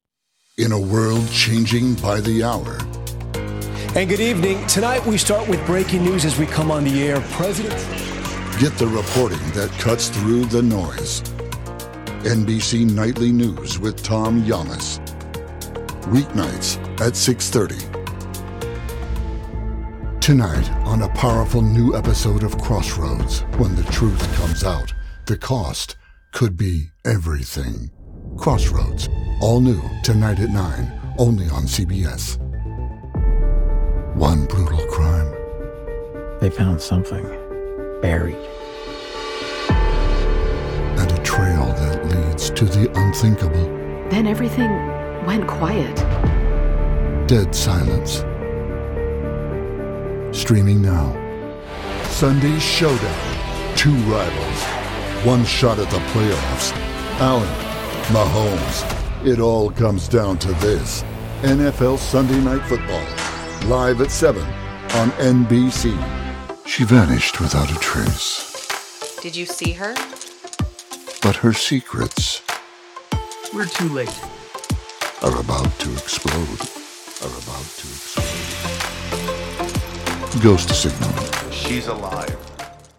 Male
Adult (30-50), Older Sound (50+)
Announcer, Authoritative, Charismatic, Deep, Empathetic, Trailer, Natural, Rich, Strong, Compelling, Sophisticated, Warm, Wise
Main Demo
Commercial Reel - Actual Work